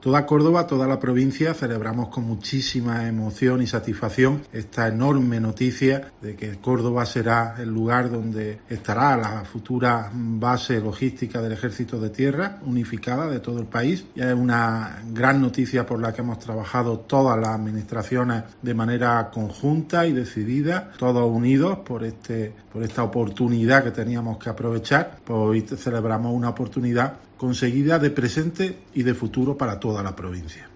Antonio Ruiz, Presidente de la Diputación de Córdoba